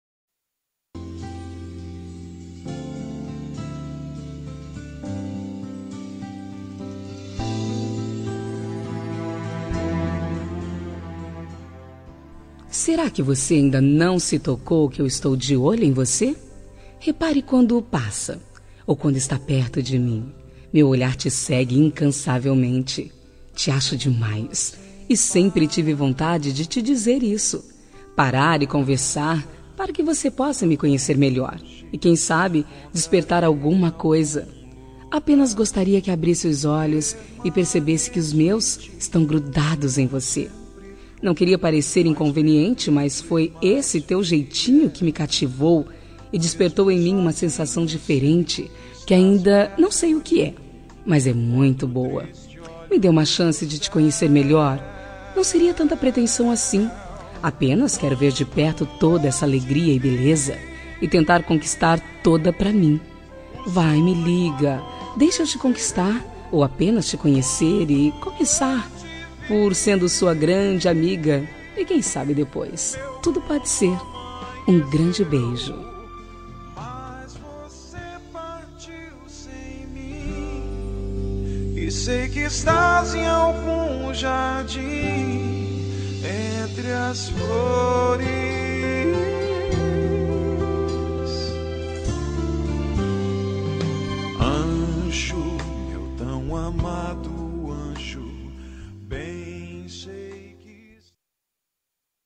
Telemensagem de Conquista – Voz Feminina – Cód: 140117